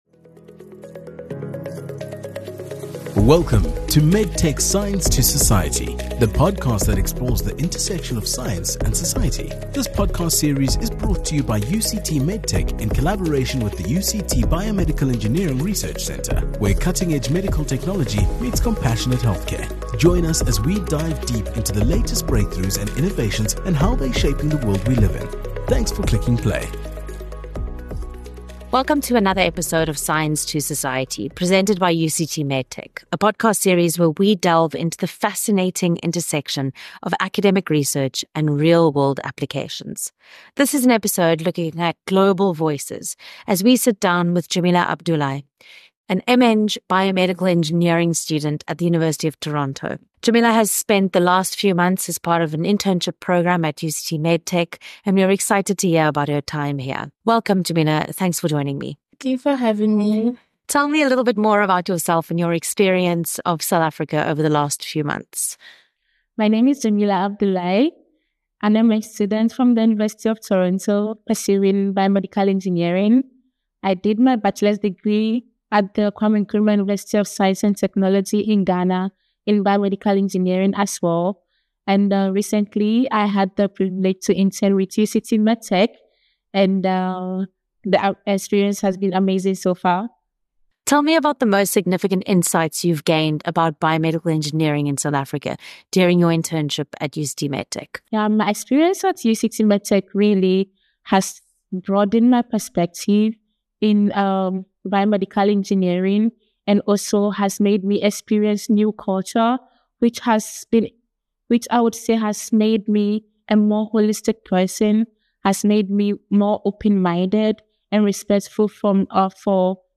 A Conversation with UCT MedTech intern